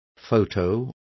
Complete with pronunciation of the translation of photos.